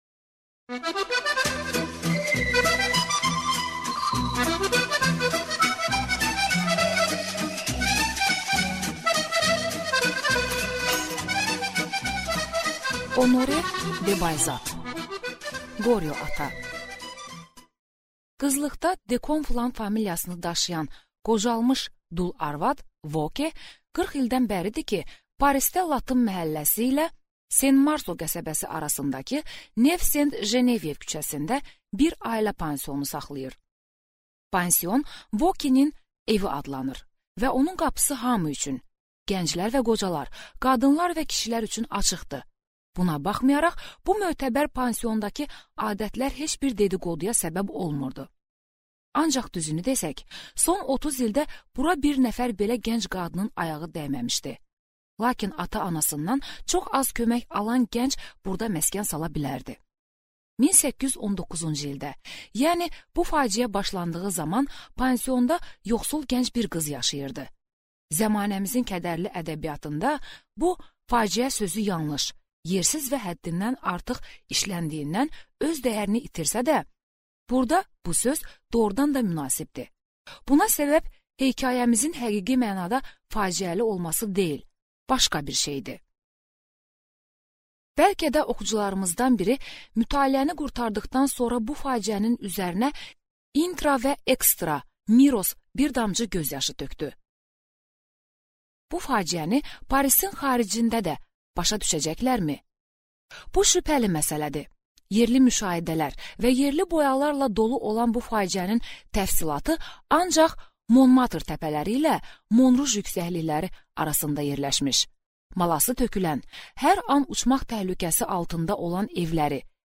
Аудиокнига Qorio ata | Библиотека аудиокниг